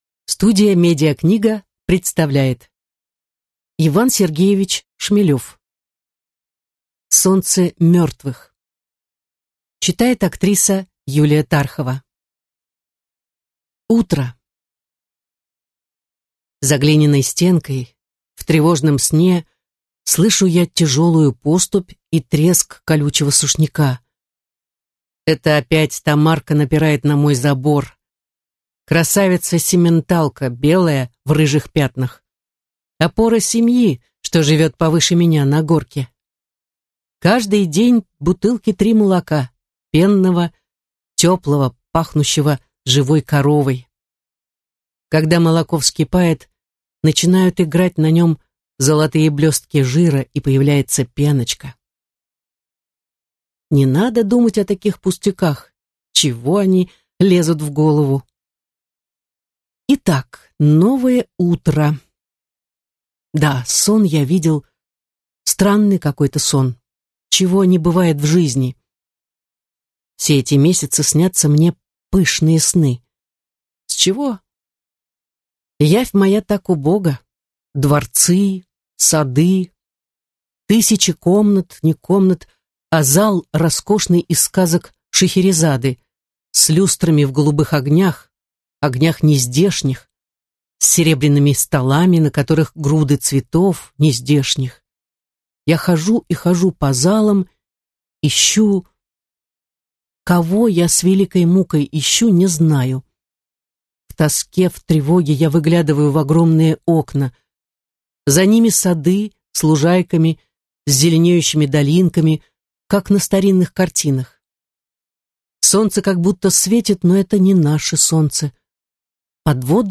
Аудиокнига Солнце мертвых | Библиотека аудиокниг